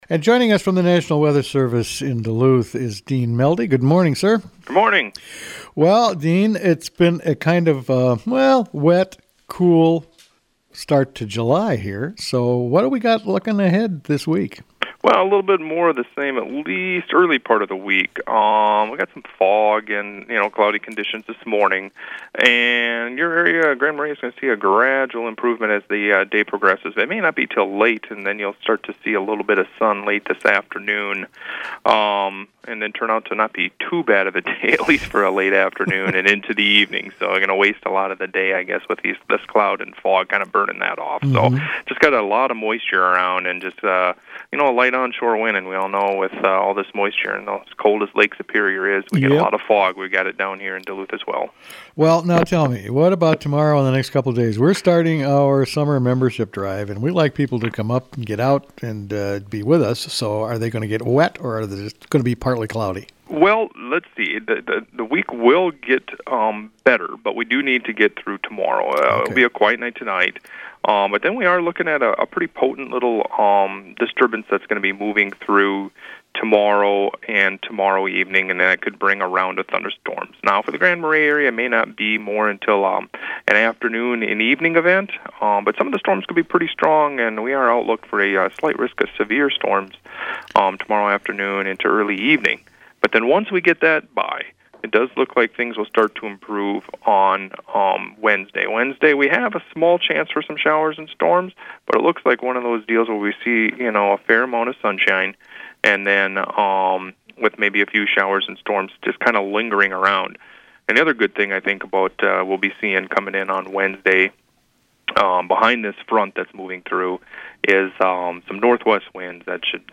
spoke with National Weather Service meteorologist